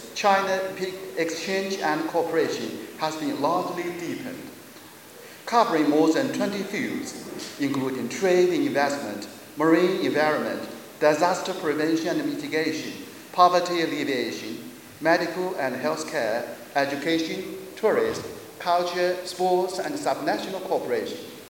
[Chinese Ambassador Zhou Jian]